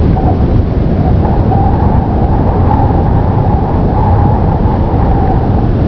windhowl.wav